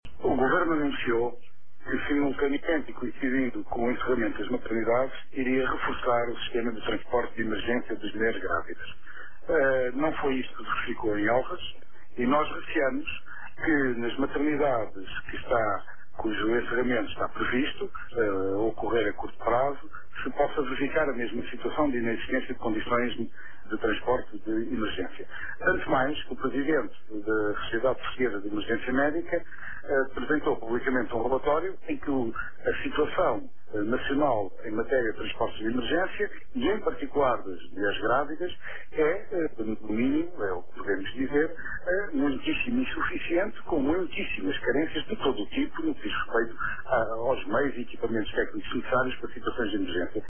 Declarações de João Semedo à TSF